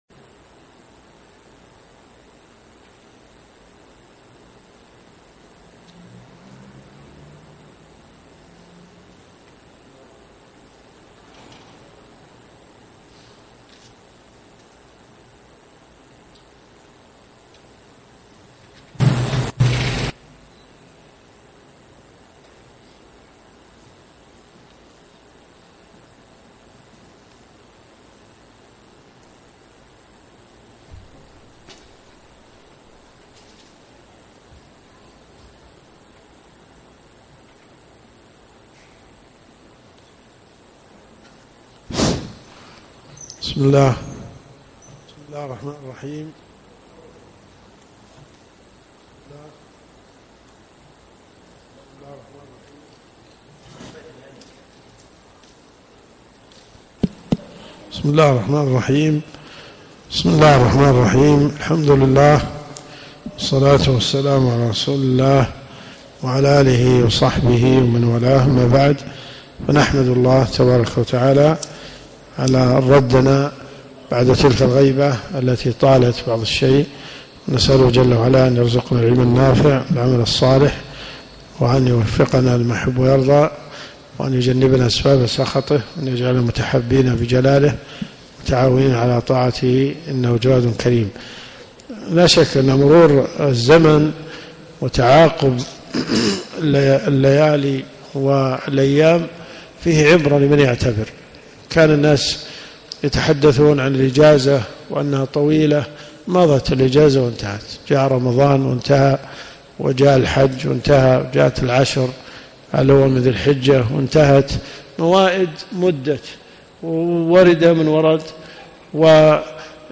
الدرس في الدقيقة 7.35 .